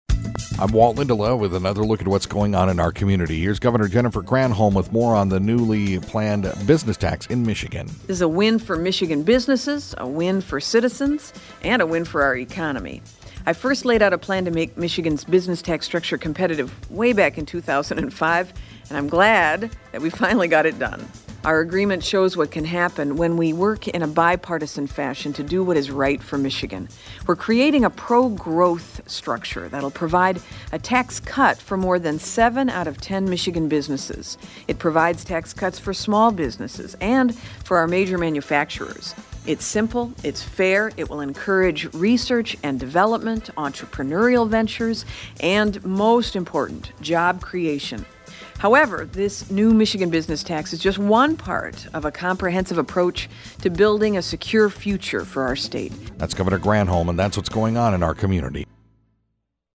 INTERVIEW: Governor Jennifer Granholm